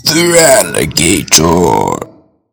FERALIGATR.mp3